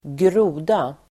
Ladda ner uttalet
groda substantiv, frog Uttal: [²gr'o:da] Böjningar: grodan, grodor Synonymer: groddjur, tabbe Definition: kräldjur tillhörande klassen Amphibia el släktet Rana Sammansättningar: lövgroda (tree frog), grod|lår (frog leg)